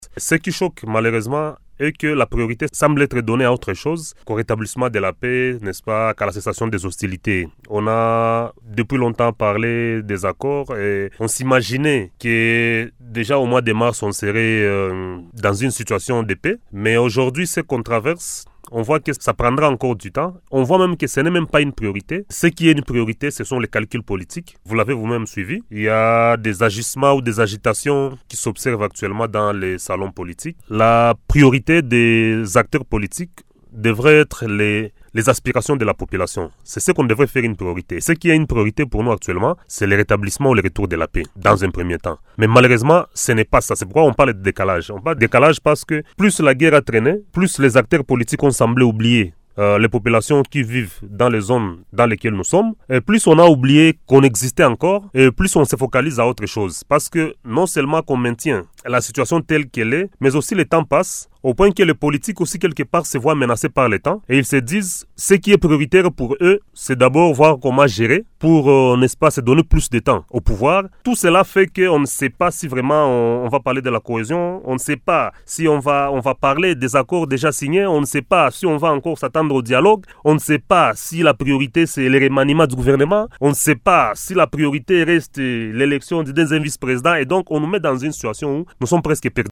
Il l’a exprimée dans une interview accordée à Radio Maendeleo, jeudi 19 mars 2026.